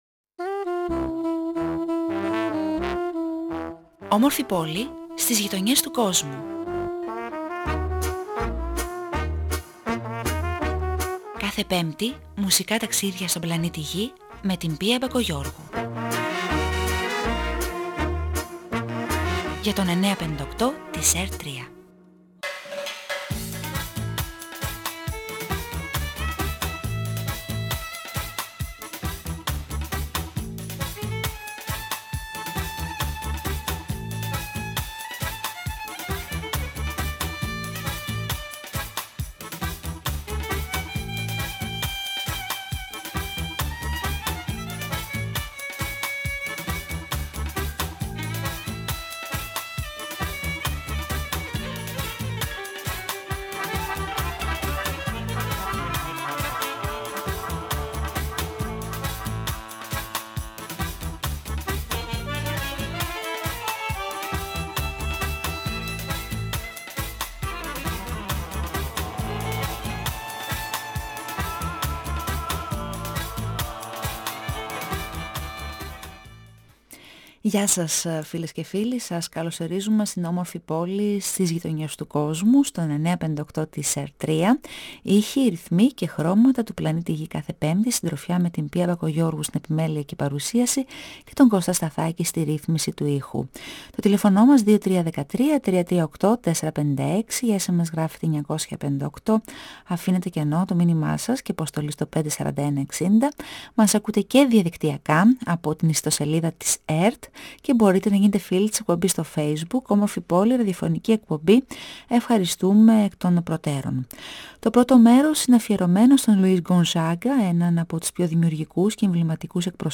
Στο β’ μέρος ταξιδεύουμε στην Ρεουνιόν, Αϊτή κ.α. 958FM